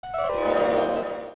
off is an edited version of a stock sound by Warner Bros. Sound Effects Library, consisting of music bells ringing. Interestingly, the item uses only the beginning portion of the original sound effect.